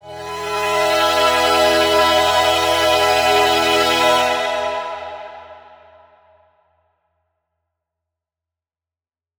Chords_Emaj_02.wav